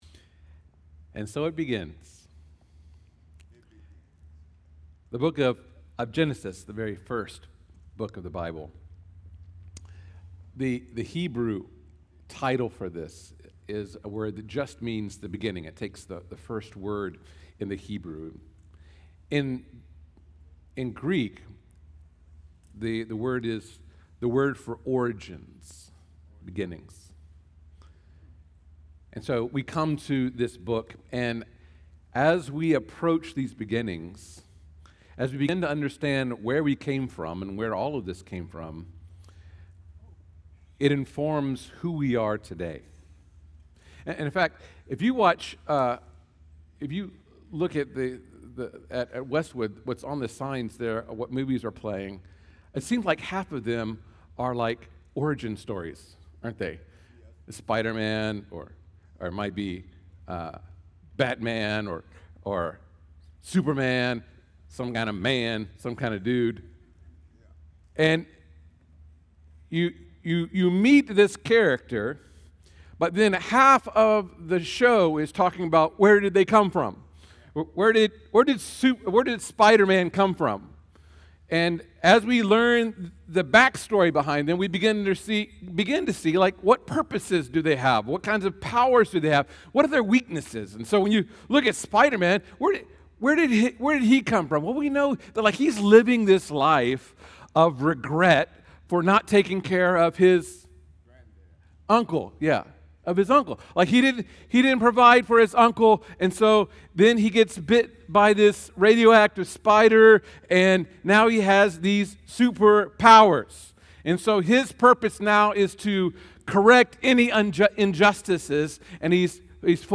Category: Sermons